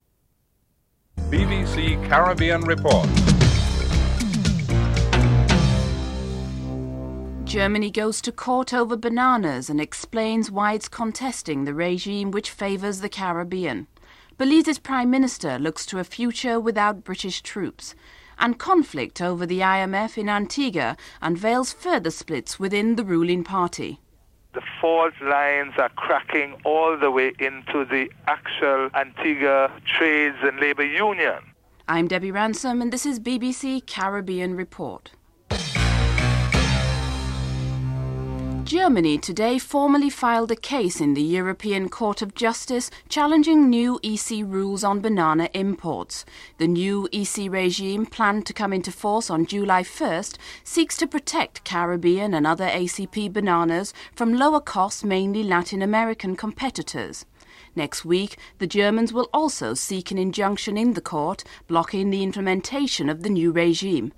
1. Headlines (00:00-00:35)